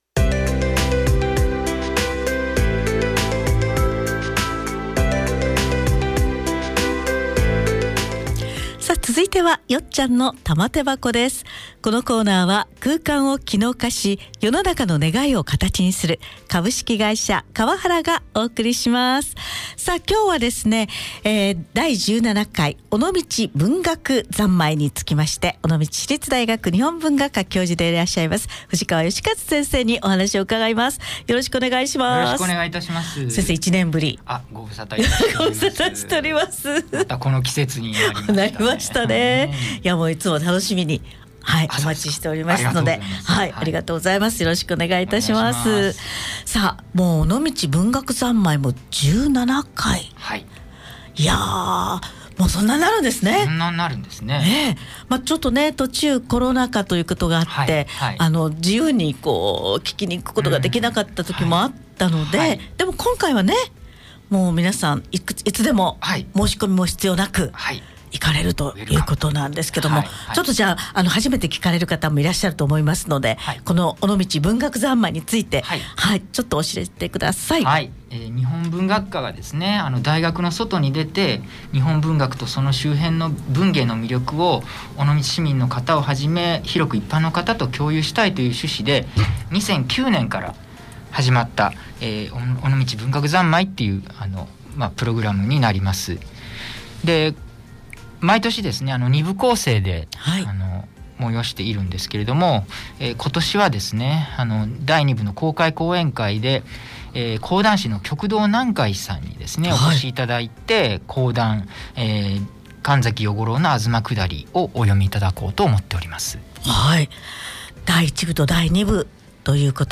毎月第1水曜日は尾道市立大学の先生をお迎えしています。